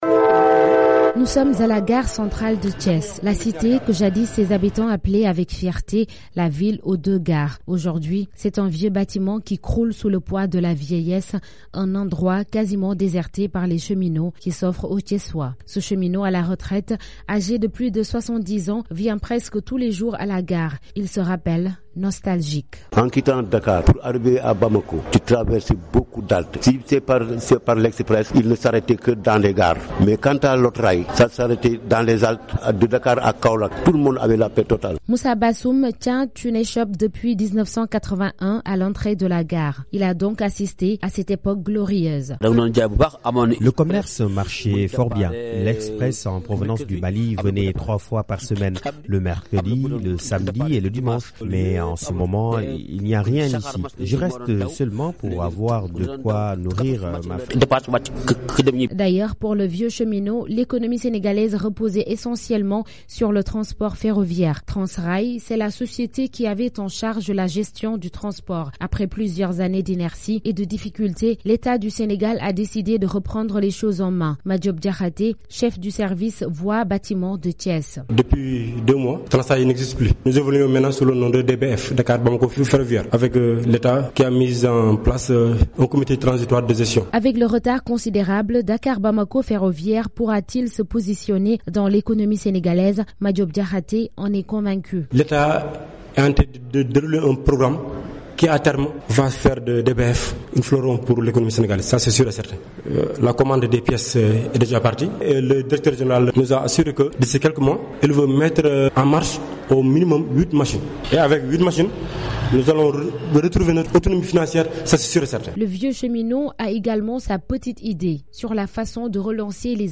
Brèves Sonores
L'Etat du Sénégal est décidé à relancer le transport ferroviaire avec une nouvelle société qui remplace Transrail. Thiès est la ville symbole du secteur. Notre reporter a fait un tour à la gare qui attend de voir se réaliser les promesses du nouveau régime.